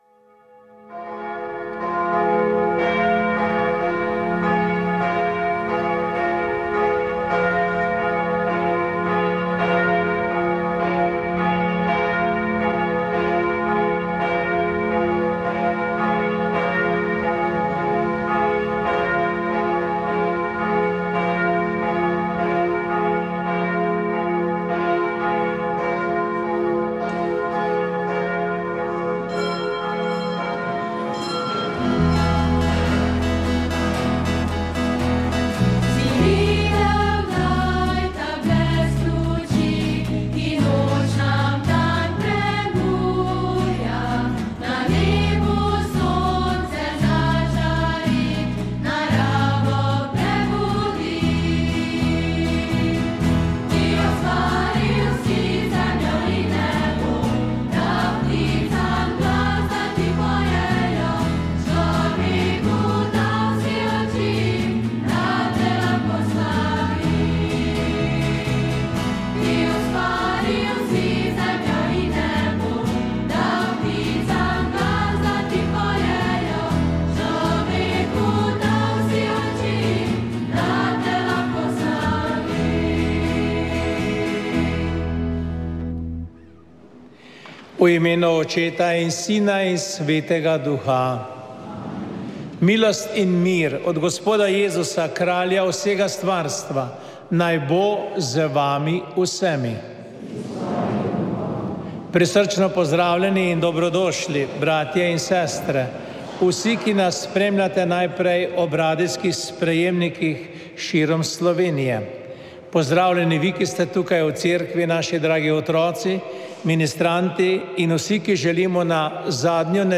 Sveta maša
Sv. maša iz cerkve sv. Marka na Markovcu v Kopru 1. 1.